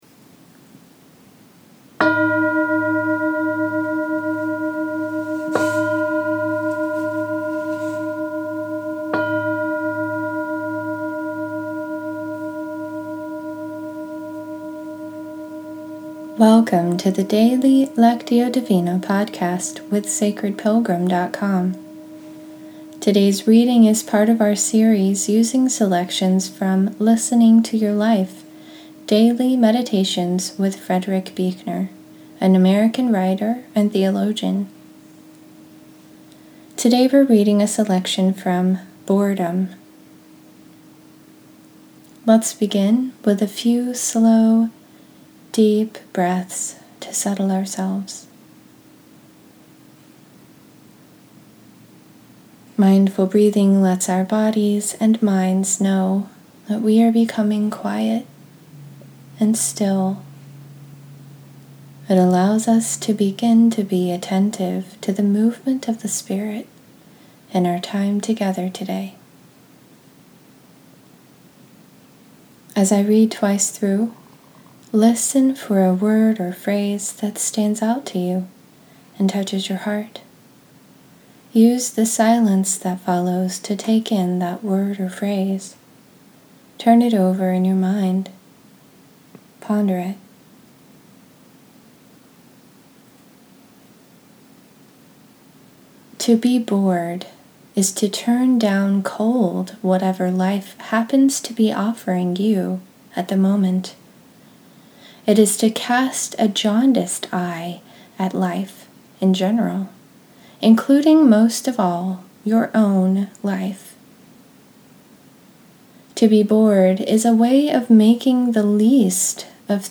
In this episode, we’re continuing our series using selections from Listening to Your Life: Daily Meditations with Frederick Buechner, an American writer and theologian. Today we’re reading a selection from “Boredom.”